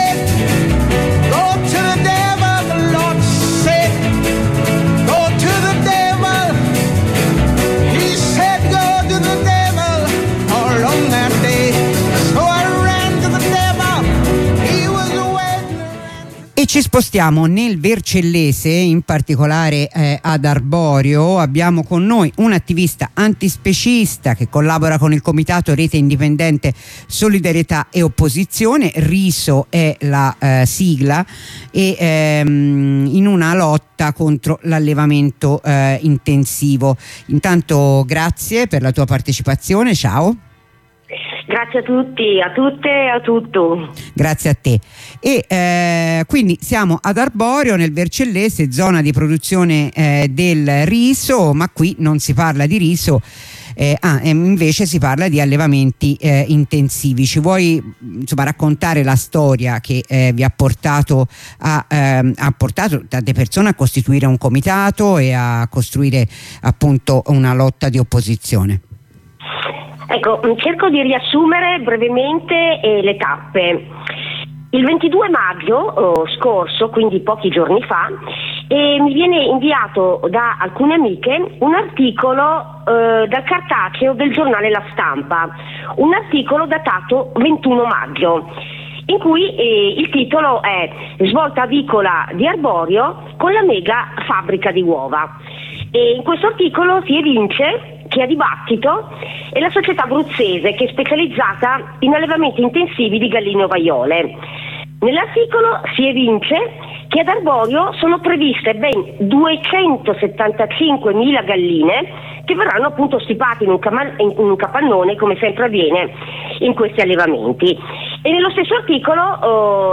Ne parliamo con un'attivista antispecista che collabora con il comitato R.I.S.O.